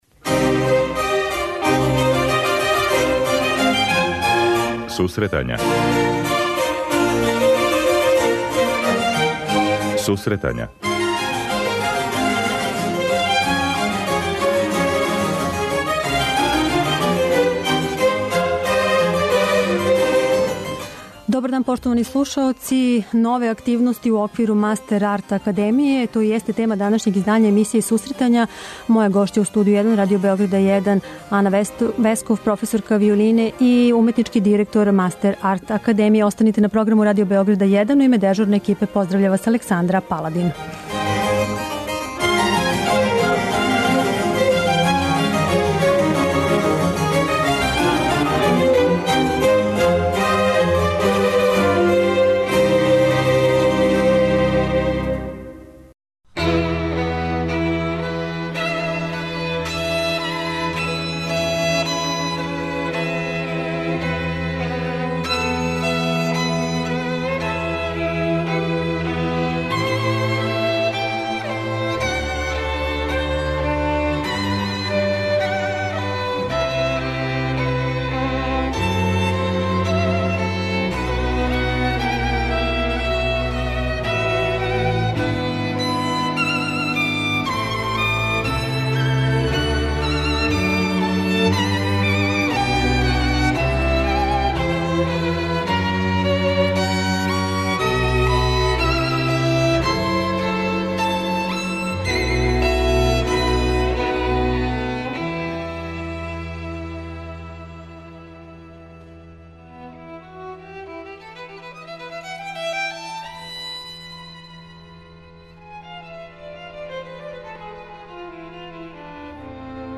преузми : 26.17 MB Сусретања Autor: Музичка редакција Емисија за оне који воле уметничку музику.